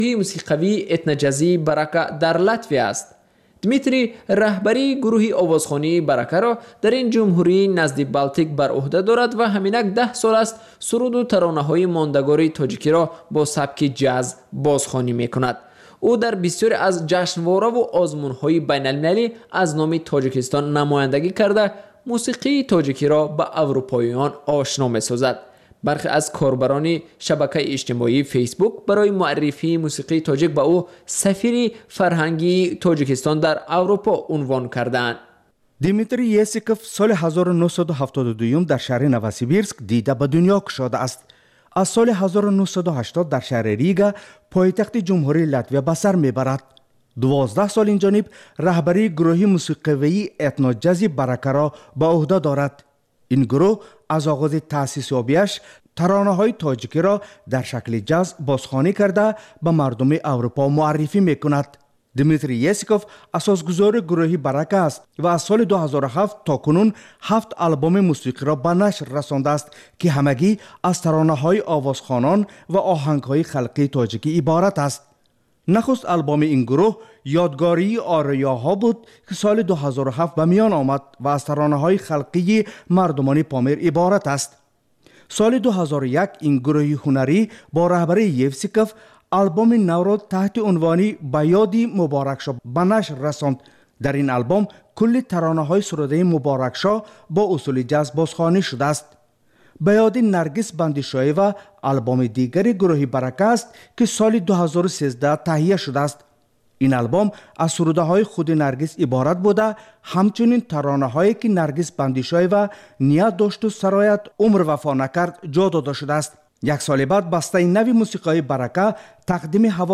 Гуфтугӯи ошкоро бо шахсони саршинос ва мӯътабари Тоҷикистон, сиёсатмадорону ҷомеашиносон, ҳунармандону фарҳангиён